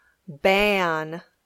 English Pronunciation of the æ and ^ Sounds - Learn English Online